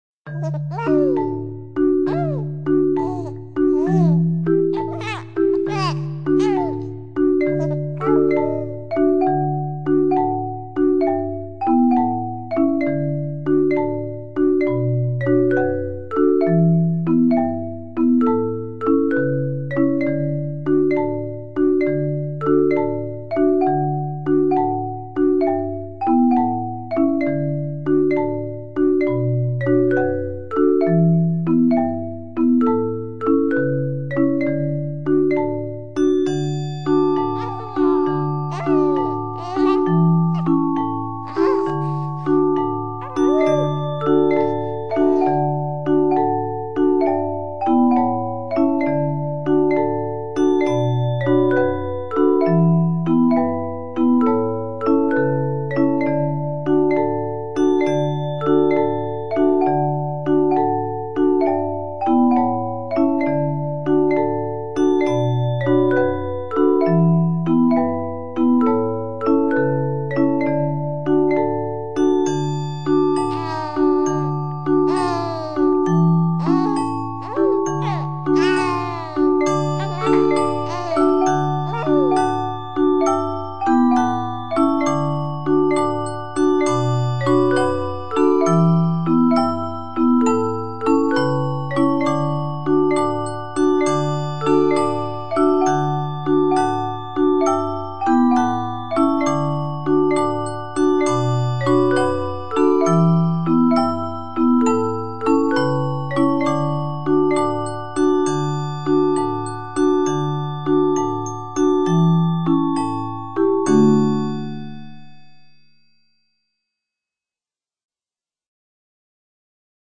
Música infantil
Canciones